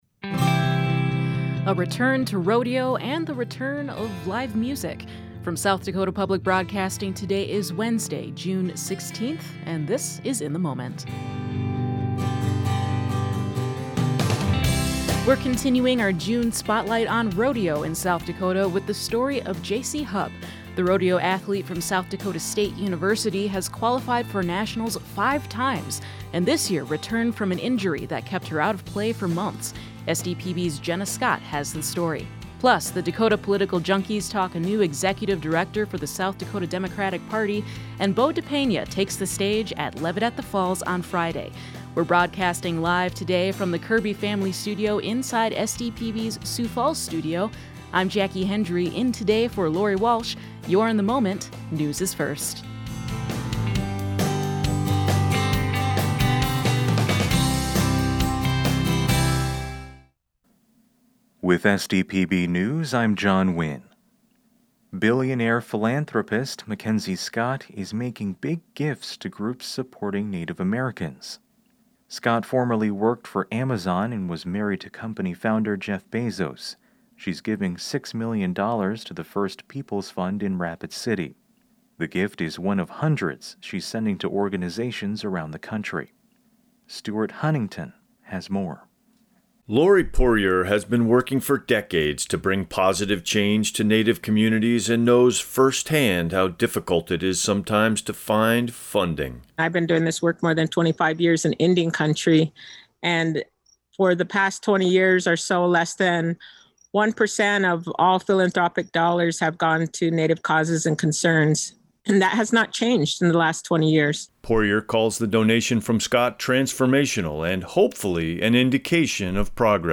In the Moment is SDPB’s daily news and culture magazine program.
More than 54 percent of South Dakota is now impacted by severe drought. State Climatologist Laura Edwards joins us with an update.